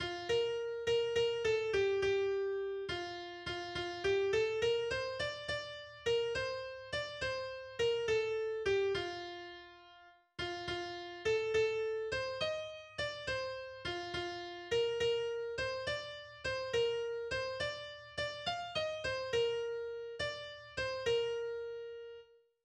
Weihnachtsfreude ist ein Weihnachtslied von Robert Reinick, das auf eine ältere Volksweise gesungen wird.